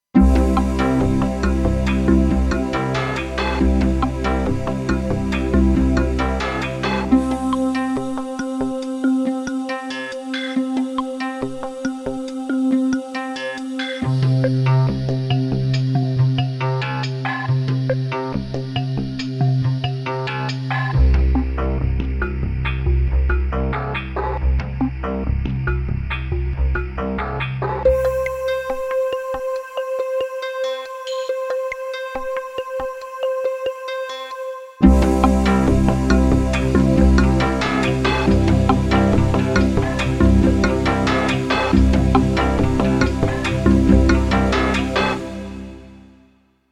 In case you were wondering what the Wavestation sounds like, here’s a sample:
This recording isn’t of me playing a tune, but just holding down one or more keys. It shows the sort of complex sounds that the Wavestation can make.
korg-wavestation-the-wave-song.mp3